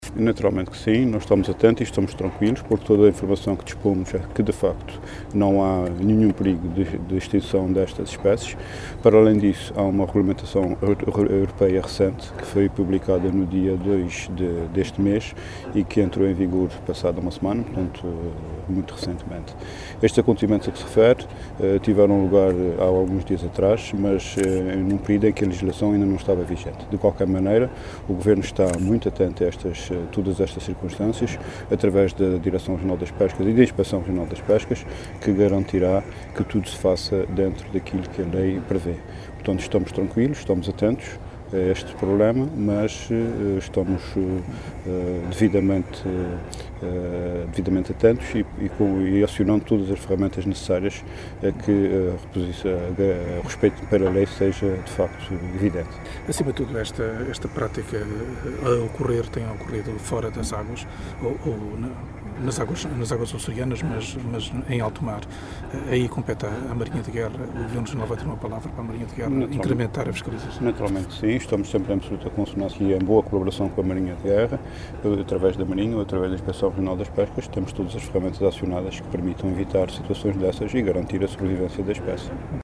Luís Neto Viveiros, em declarações aos jornalistas na Horta, recordou que esta regulamentação europeia “só entrou em vigor passada uma semana” sobre a situação que envolveu a embarcação espanhola, não abrangendo, por isso, o período de pesca da embarcação em causa.